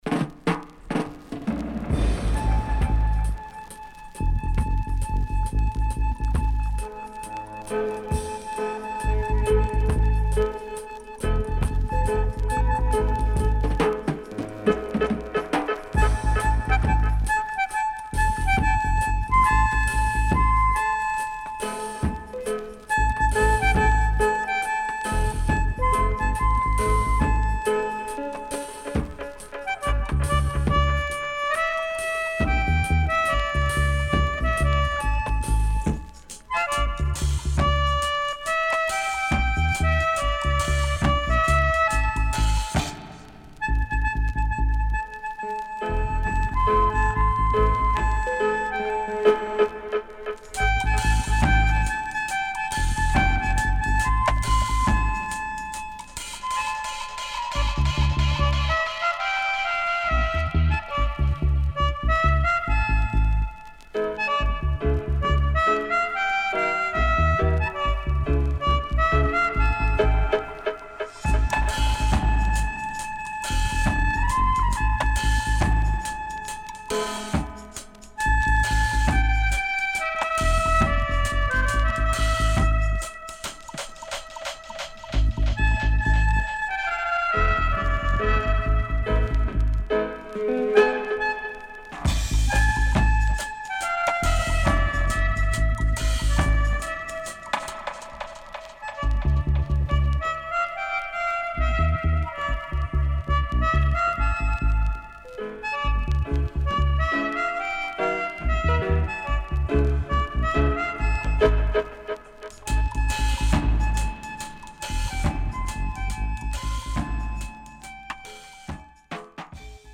SIDE A:プレス起因により全体的にチリノイズ入ります。